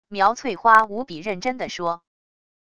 苗翠花无比认真的说wav音频